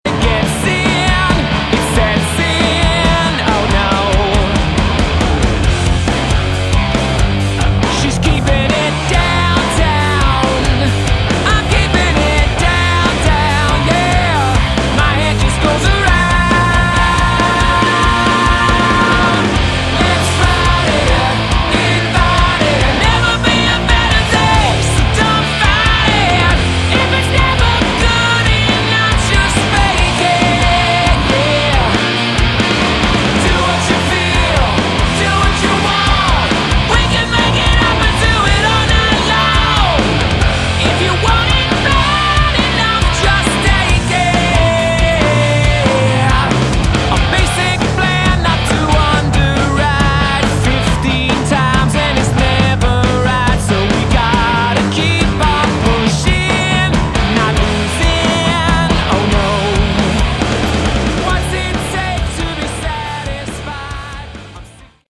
Category: Hard Rock
lead vocals, bass
guitar
drums